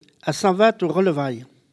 Collectif patois et dariolage
Catégorie Locution